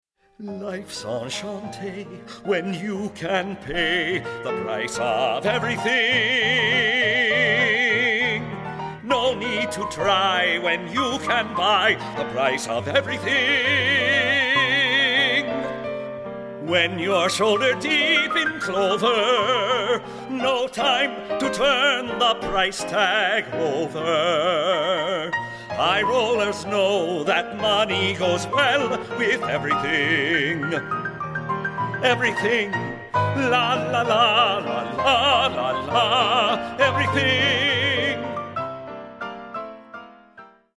A Vaudeville Musical For Our Time
With unforgettable toe-tapping songs